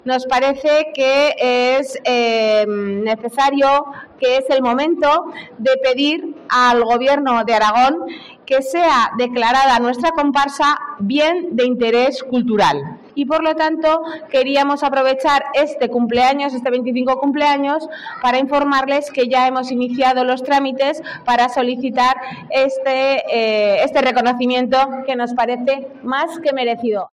La alcaldesa anuncia la solicitud de la declaración de BIC para la comparsa de Gigantes y Cabezudos